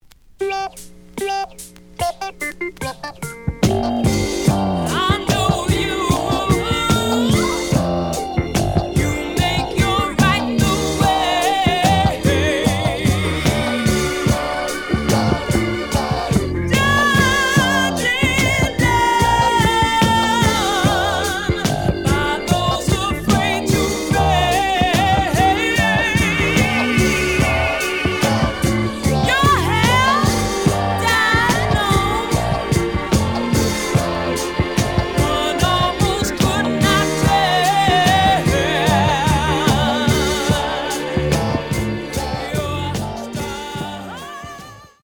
The audio sample is recorded from the actual item.
●Genre: Funk, 70's Funk
●Record Grading: VG~VG+ (傷はあるが、プレイはおおむね良好。Plays good.)